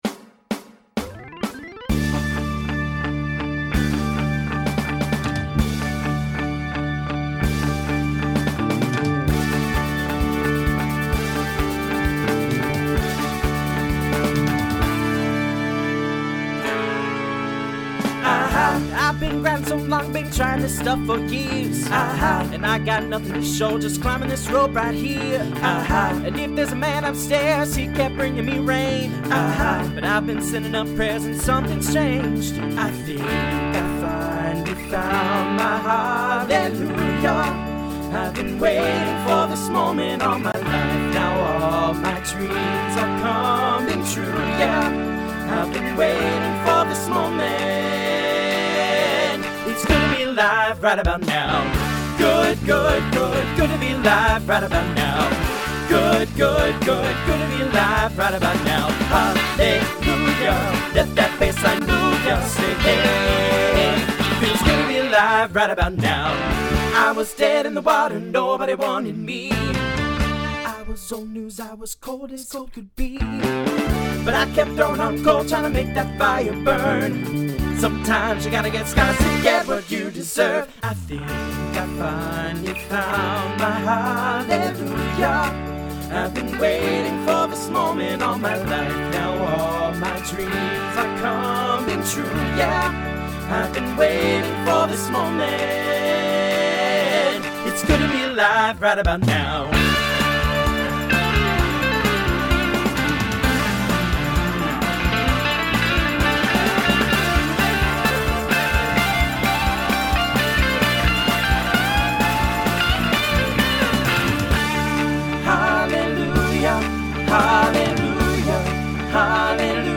Voicing TTB Instrumental combo Genre Country , Pop/Dance